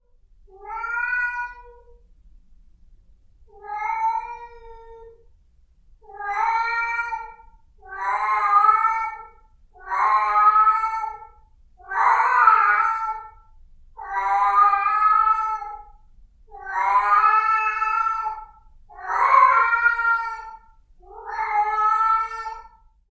gato4b
cat4b.mp3